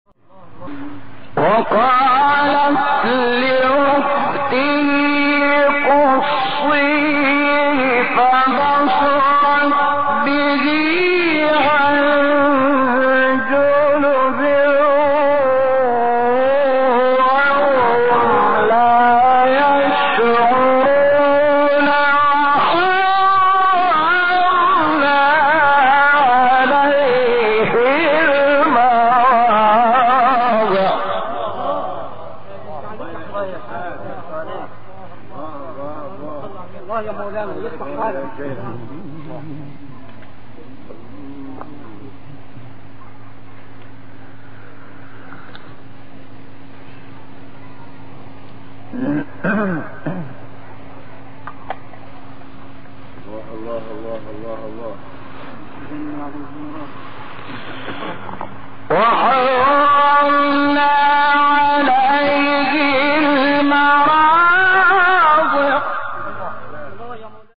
سوره : قصص آیه: 11-12 استاد : شحات محمد انور مقام : حجاز قبلی بعدی